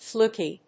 音節fluk・y, fluk・ey 発音記号・読み方/flúːki/